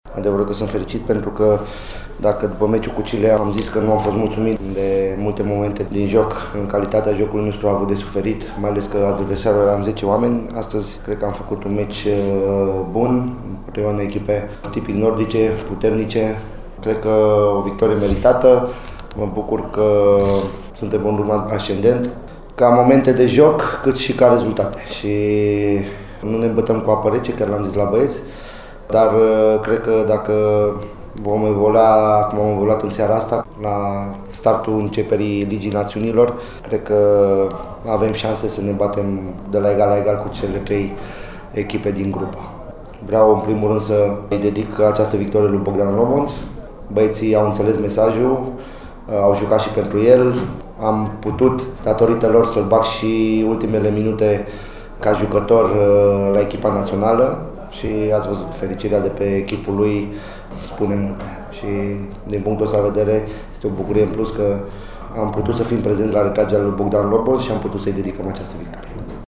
Concluziile partidei au fost trase de selecționerul Cosmin Contra.